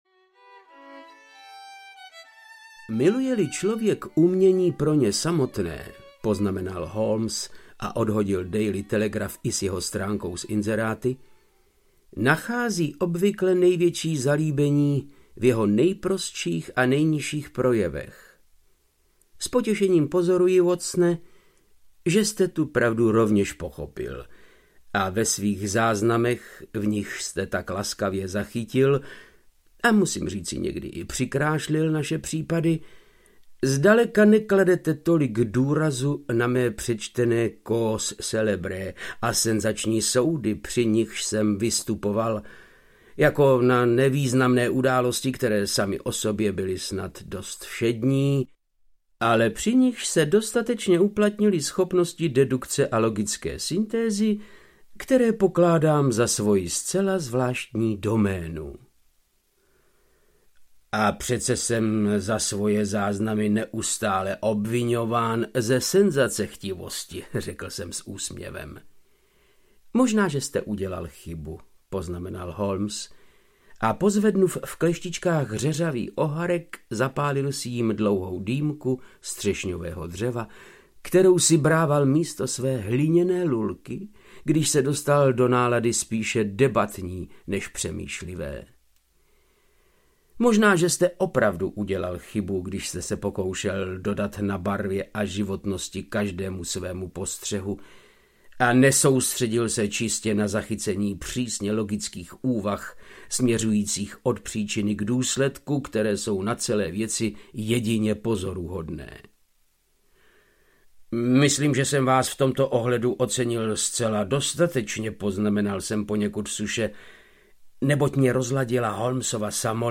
Dům U měděných buků audiokniha
Ukázka z knihy
• InterpretVáclav Knop